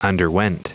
Prononciation du mot underwent en anglais (fichier audio)
Prononciation du mot : underwent